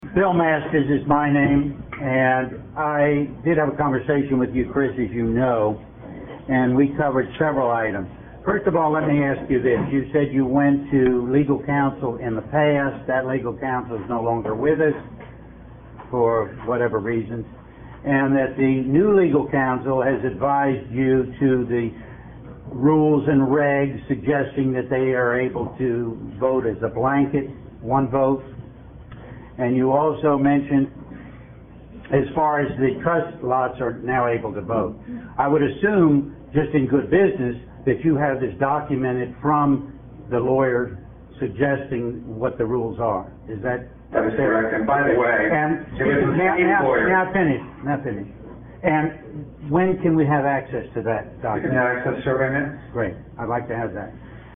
Keep in mind this was a promise made in an open, recorded public meeting about providing access to a report paid for by members.